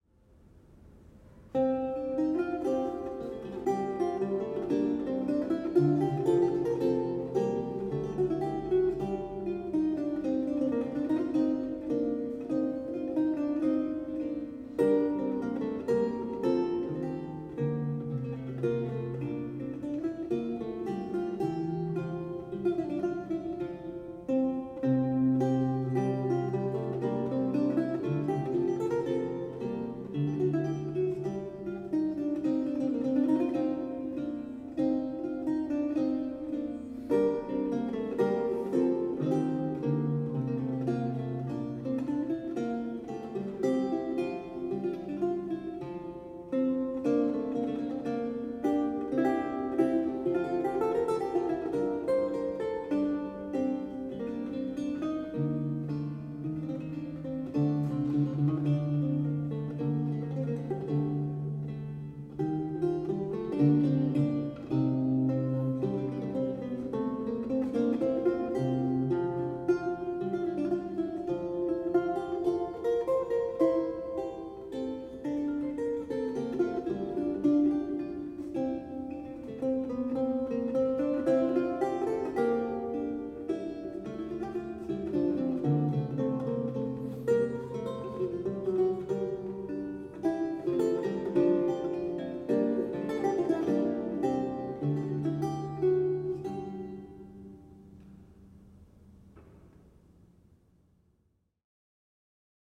a 16th century lute music piece originally notated in lute tablature
Audio recording of a lute piece